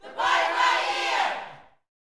UYC PARTY.wav